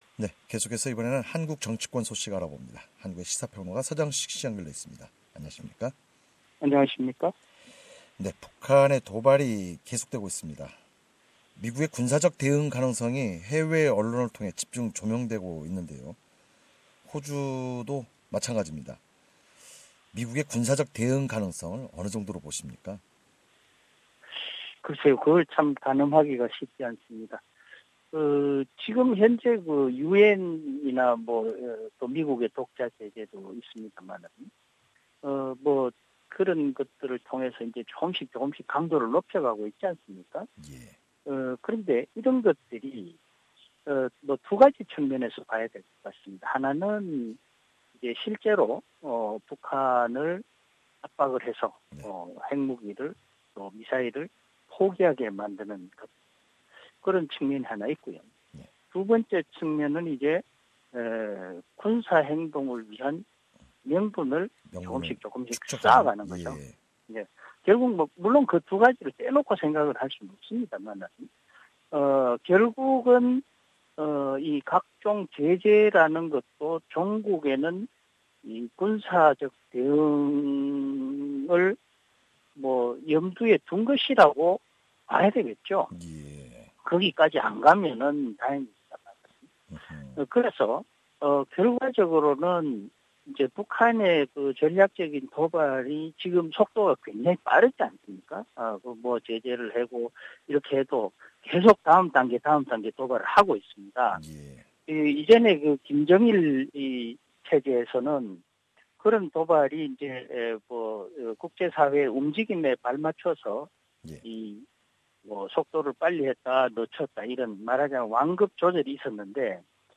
시사 평론가